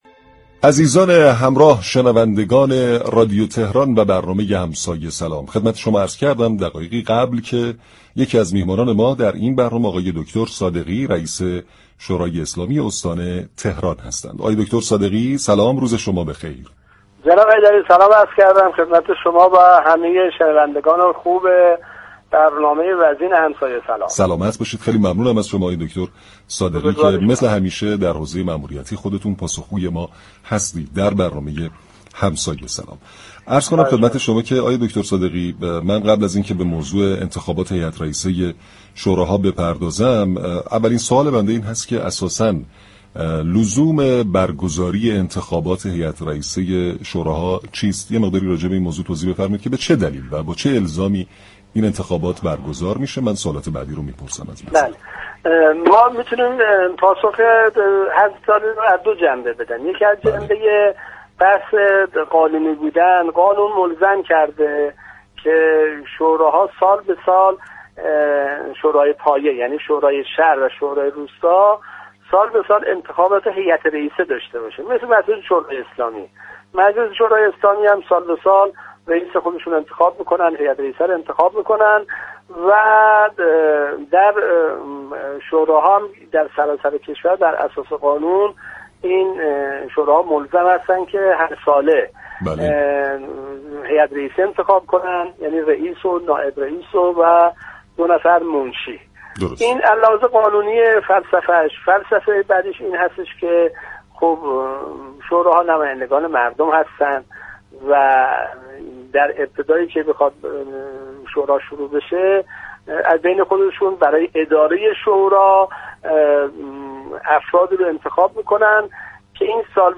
رئیس شورای اسلامی استان تهران در گفت و گو با رادیو تهران ، اعلام كرد كه تمامی شوراهای شهر و روستای استان پیش از مهلت قانونی 14 مرداد، انتخابات هیئت‌رئیسه خود را برگزار كرده‌اند تا از ابطال مصوبات به دلیل عدم وجاهت قانونی جلوگیری شود.